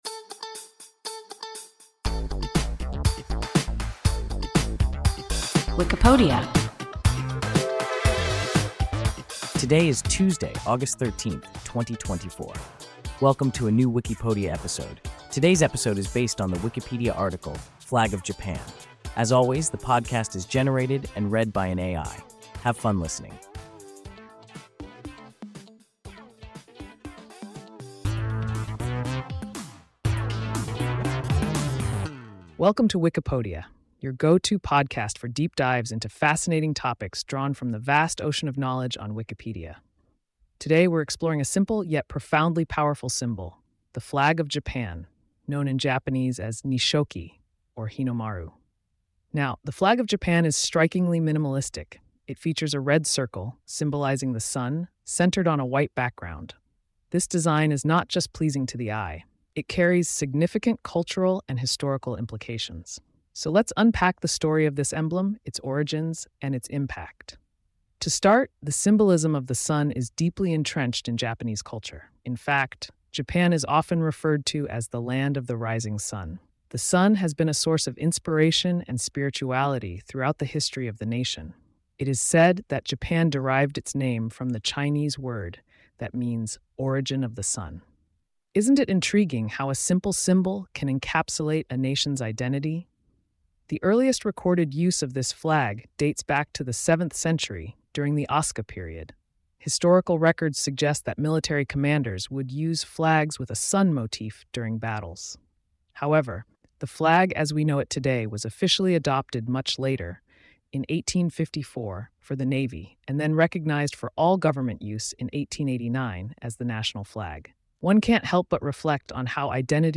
Flag of Japan – WIKIPODIA – ein KI Podcast